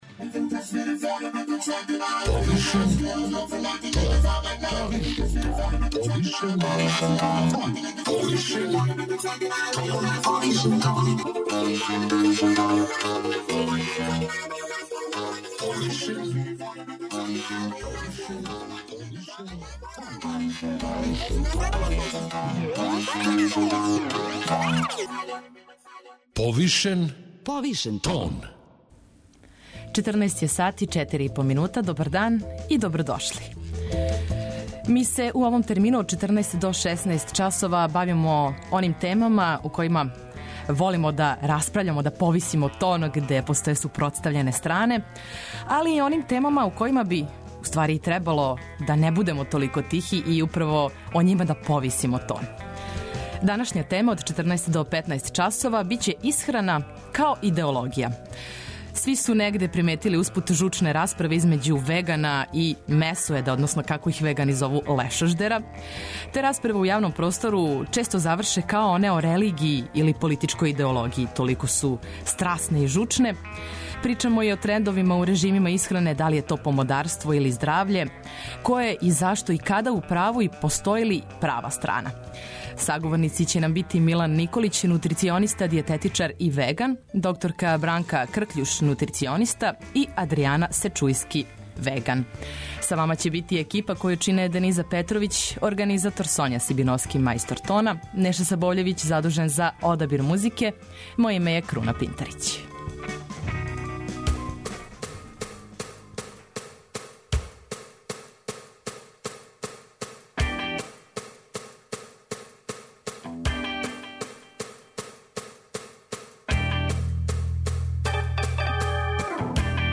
Наши саговорници су дијететичар, нутрициониста и веган